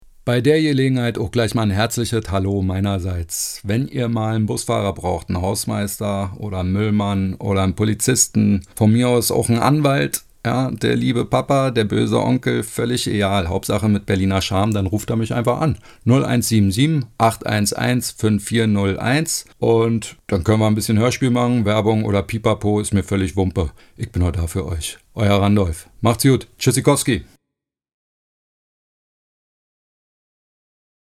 dunkel, sonor, souverän
Mittel minus (25-45)
Narrative
Berlinerisch